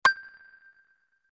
menuclick.mp3